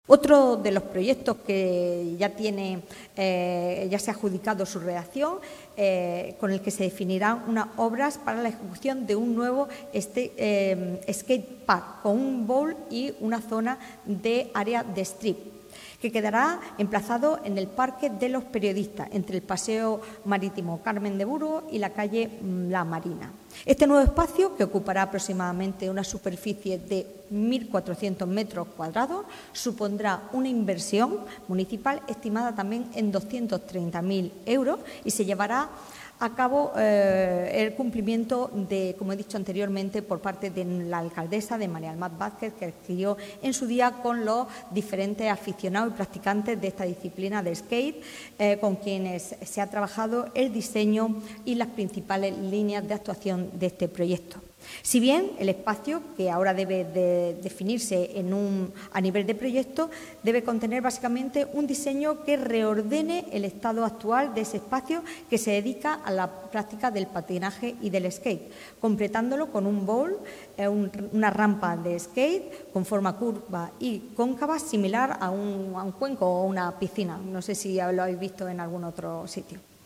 Así lo ha trasladado la portavoz del Equipo de Gobierno, Sacramento Sánchez, informando esta mañana en rueda de prensa del contenido de los acuerdos adoptados en Junta de Gobierno Local, entre los que se incluyeron la aprobación de la clasificación de proposiciones presentadas a la licitación de este proyecto, avanzando así en la adjudicación del mismo.